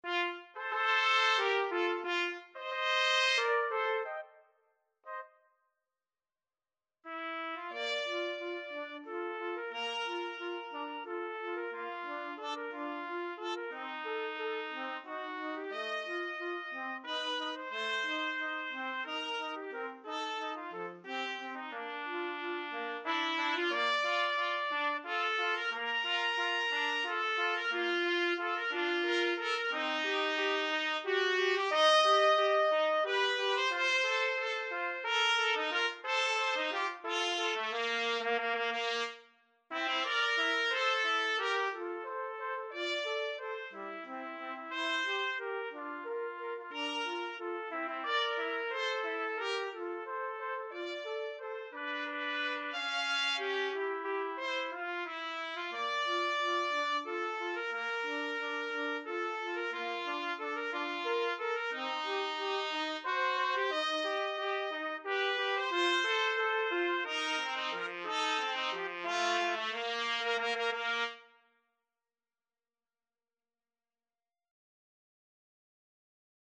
~ = 180 Tempo di Valse
3/4 (View more 3/4 Music)
Classical (View more Classical Trumpet Duet Music)